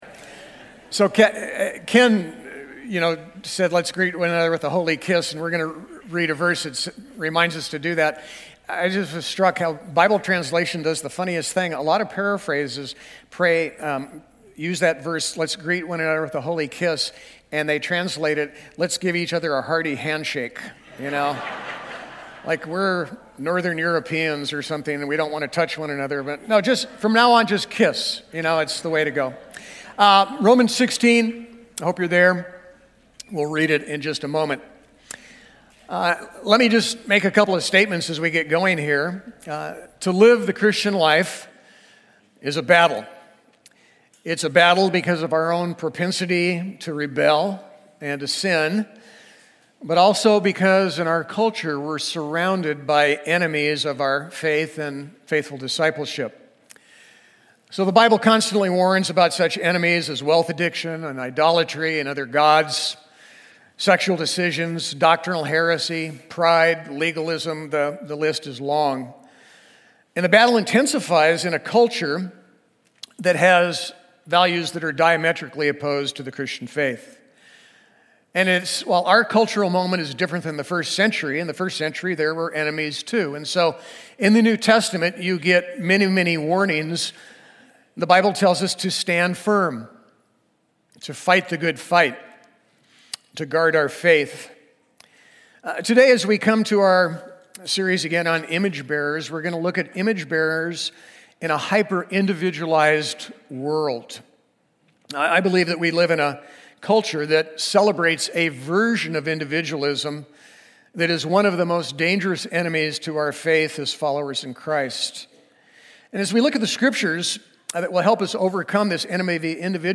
Passage: Romans 16 Service Type: Sunday